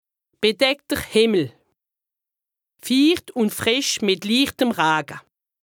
Haut Rhin
Ville Prononciation 68
Bruebach